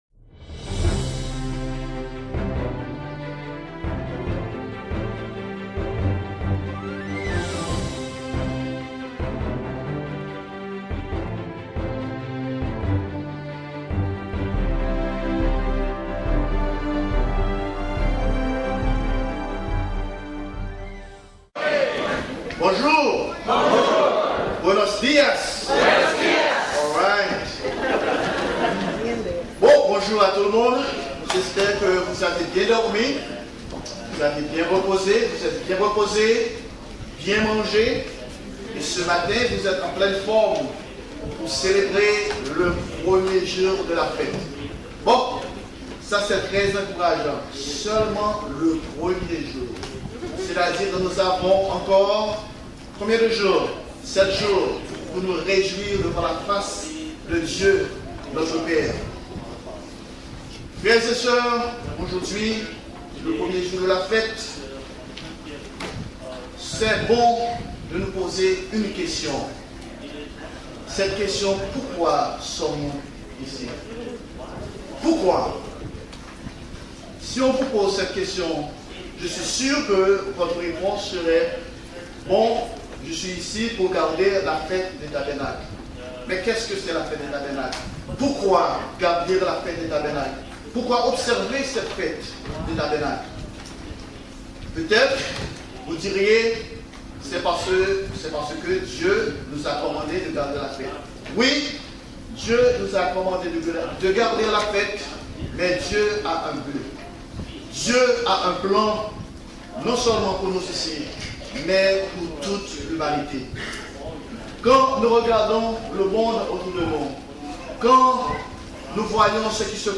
Fête des Tabernacles – 1er jour